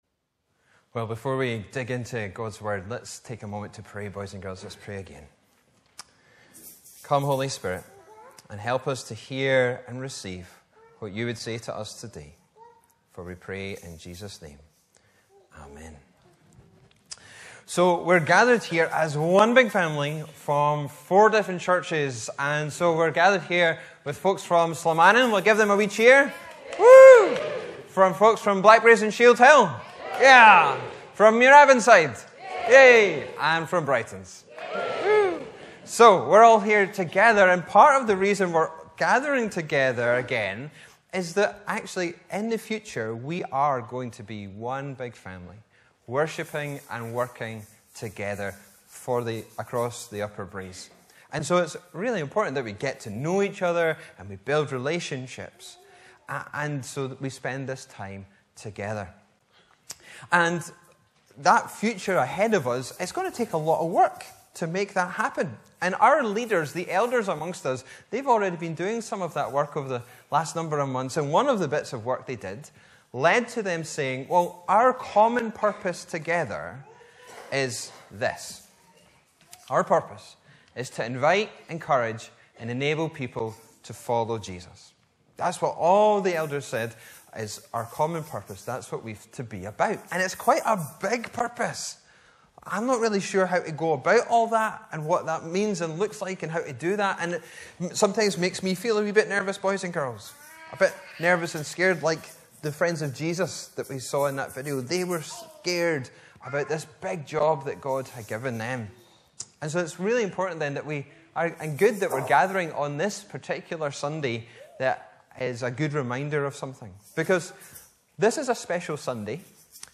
May 28, 2023 Purpose MP3 Subscribe to podcast Notes Preached on: Sunday 28th May 2023 The sermon text is available as subtitles in the Youtube video (the accuracy of which is not guaranteed).
Bible references: Acts 2 Location: Brightons Parish Church Show sermon text Sermon keypoints: Our purpose is to invite, encourage and enable people to follow Jesus